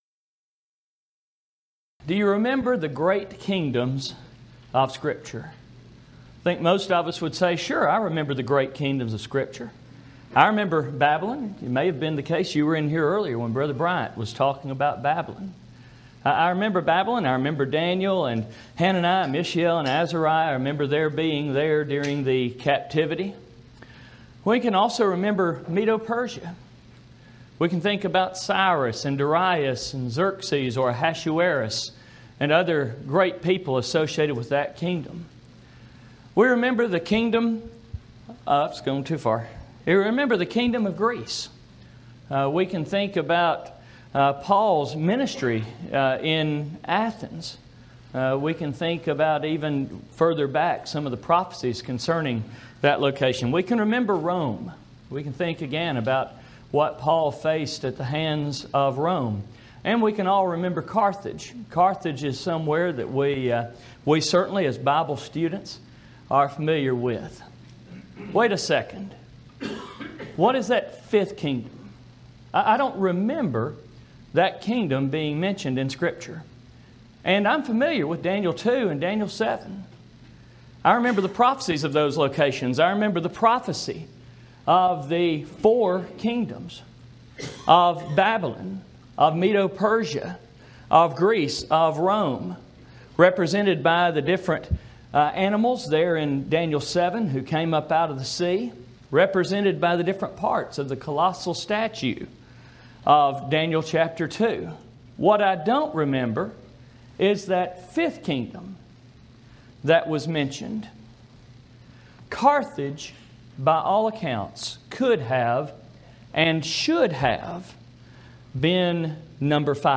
Event: 2016 Focal Point Theme/Title: Preacher's Workshop